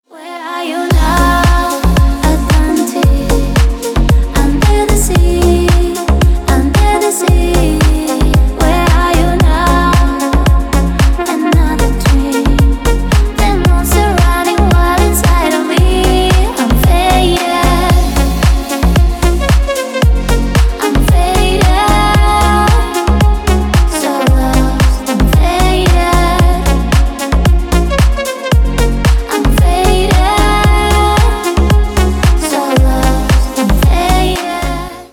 ремикс на звонок